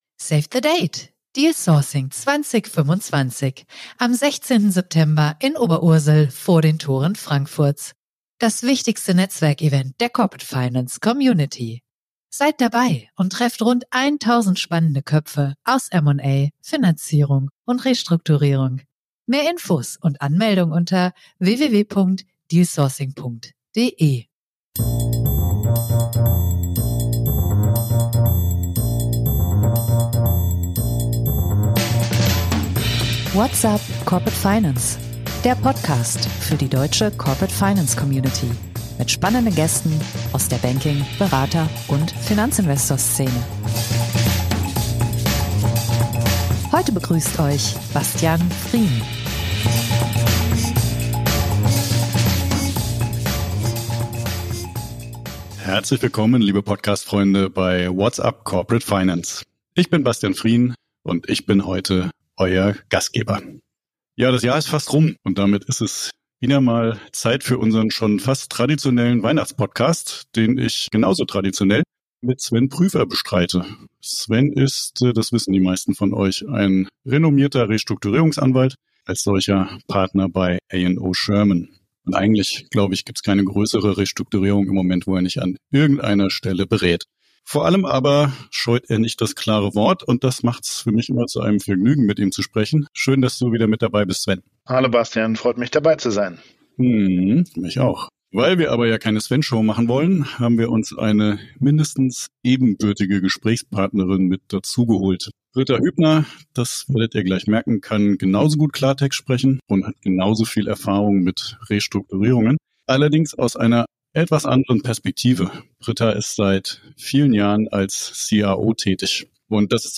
Das haben wir in unserem Weihnachtspodcast mit zwei absoluten Restrukturierungsprofis in einem ebenso offenen wie kurzweiligen Gespräch untersucht.